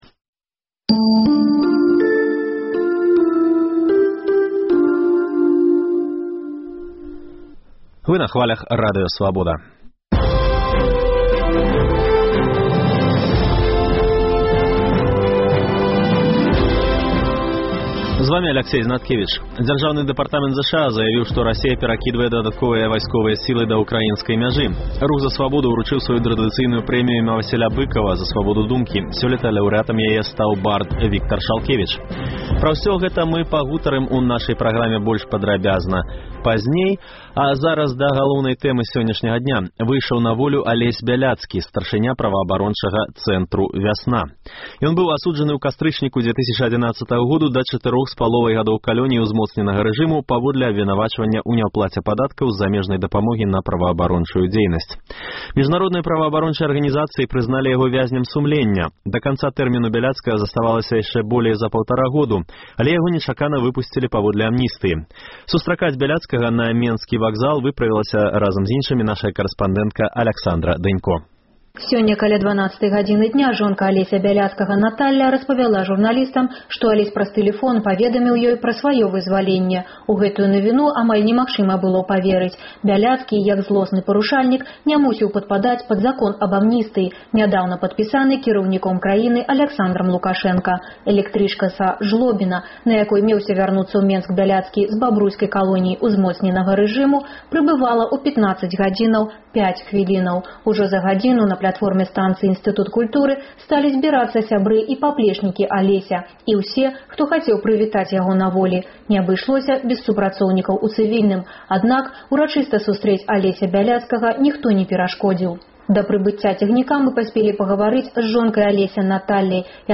Першае інтэрвію Бяляцкага на волі.